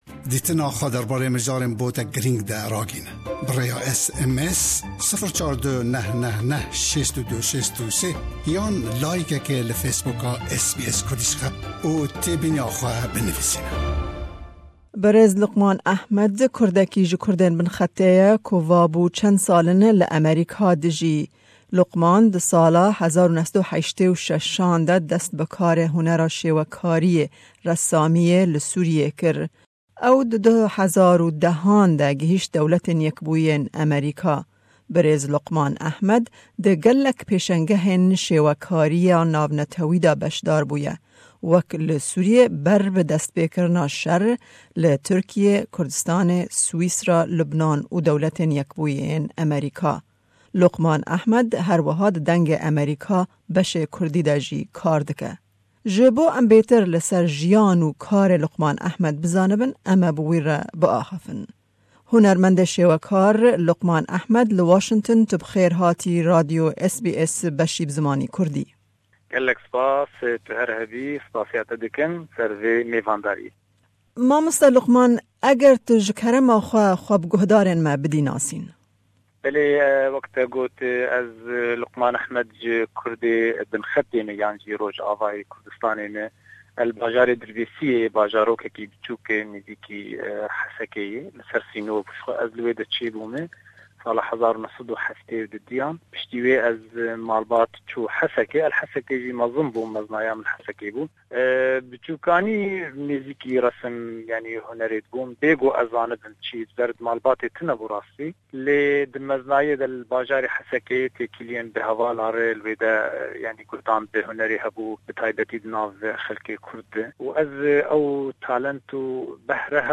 Me di hevpeyvînê de li ser jiyan, despêkirina karê hunerî û rola hunera shêwekariyê di civaka kurd de çi dileyzê pirsî.